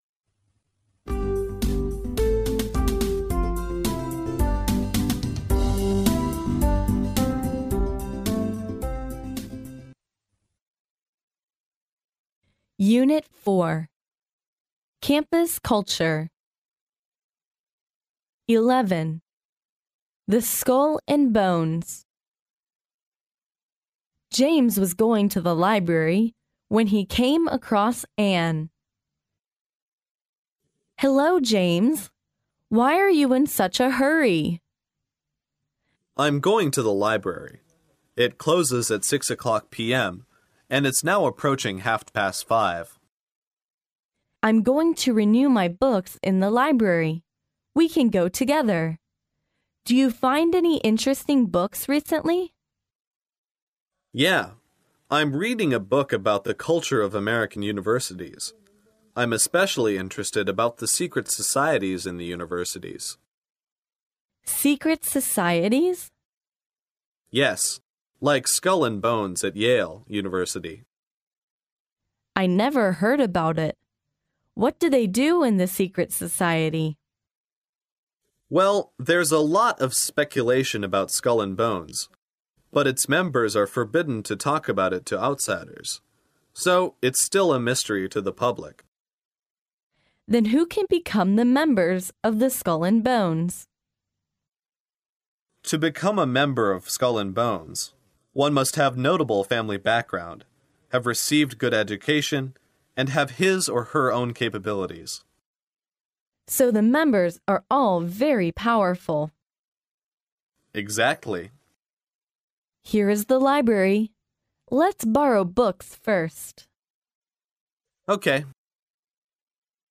耶鲁大学校园英语情景对话11：骷髅会（mp3+中英）